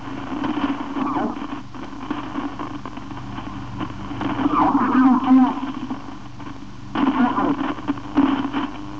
durante una registrazione con ricevitore BC 312 in onde corte alla frequenza di 6.700 Mhz